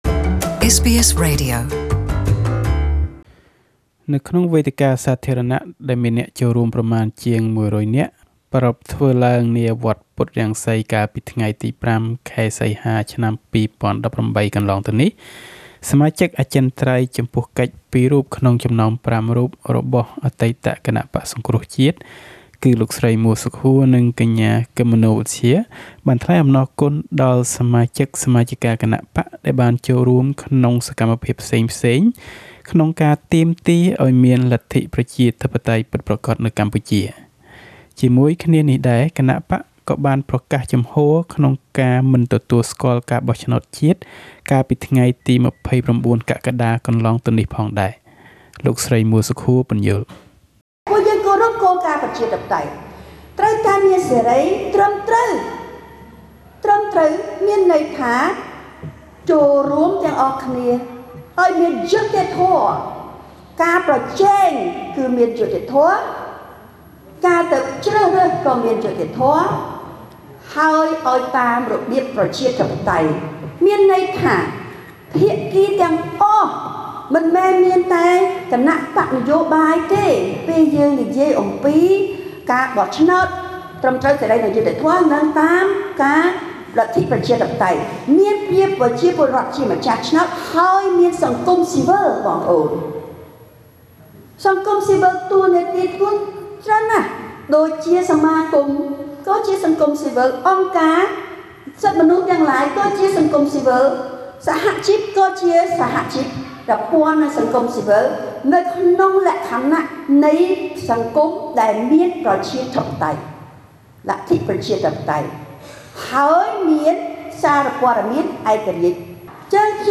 នៅក្នុងវេទិកាសាធារណៈដែលមានអ្នកចូលរួមប្រមាណជាងមួយរយនាក់ ប្រារព្ធធ្វើឡើងនាវត្តពុទ្ធរង្ស៊ី